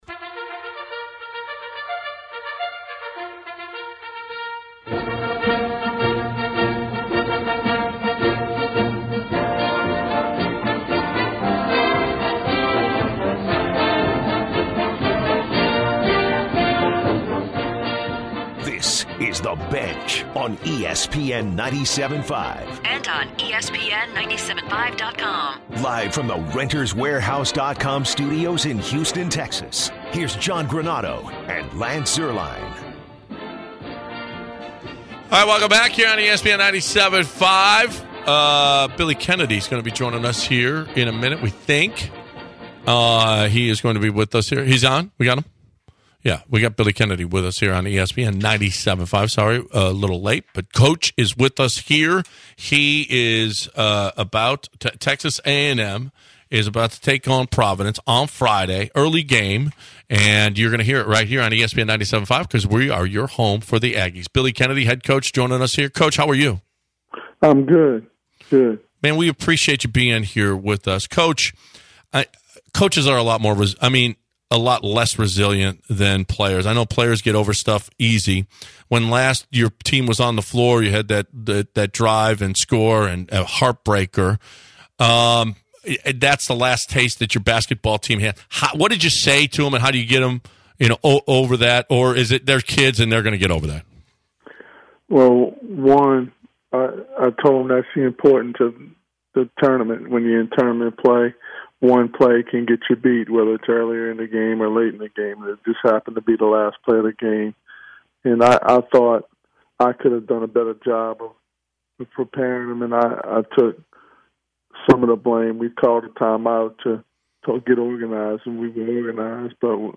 Texas A&M Head Coach Billy Kennedy joins the show to talk Texas A&M's first round matchup against Providence.